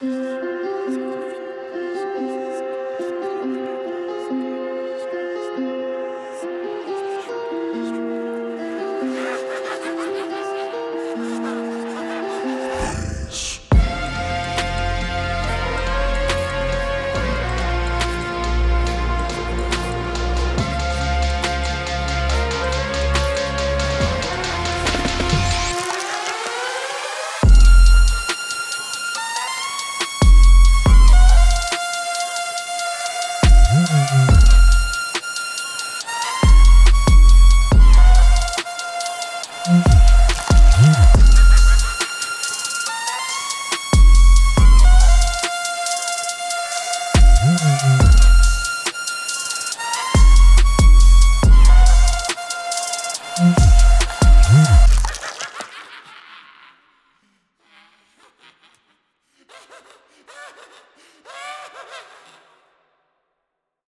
Whether crafting horror-infused Drill beats
• 66 Loops, sound FX, and one-shots